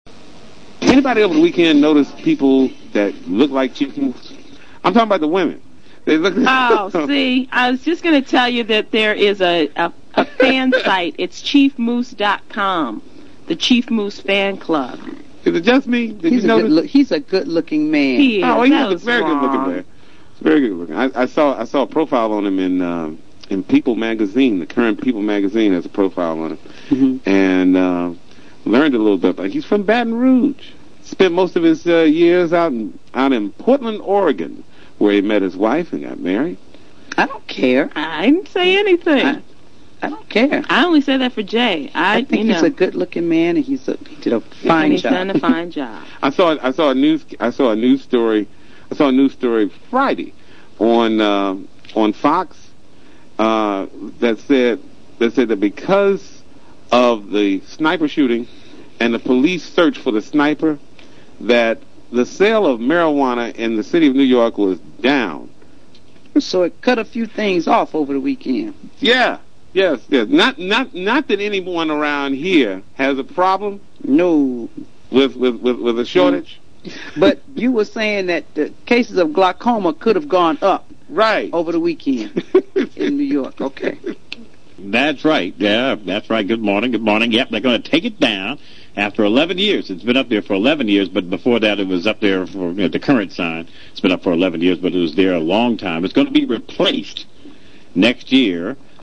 United States, African American English
UnitedStates_AAVE.wav